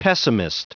Prononciation du mot pessimist en anglais (fichier audio)
Prononciation du mot : pessimist